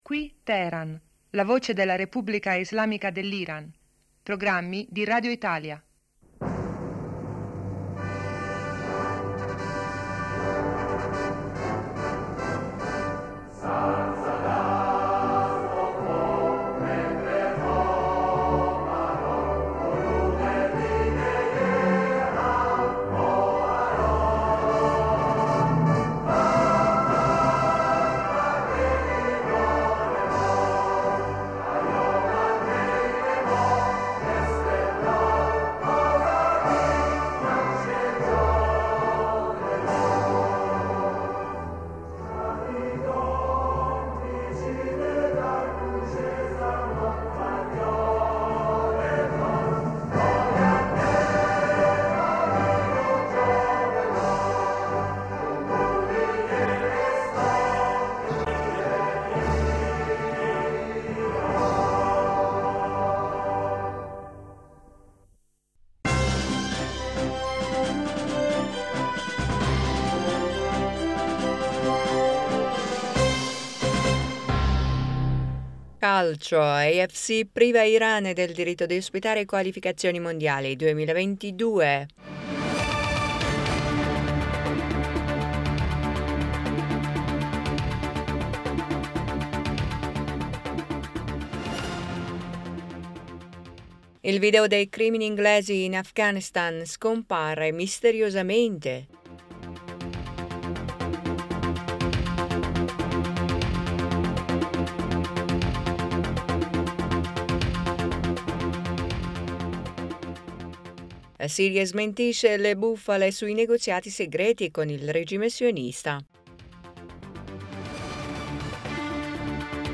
Ecco i titoli più importanti del nostro radiogiornale: 1-Calcio, AFC priva Iran del diritto di ospitare Qualificazioni mondiali a causa delle sanzioni Usa,...